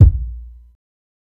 ILLMD004_KICK_MILLI_1.wav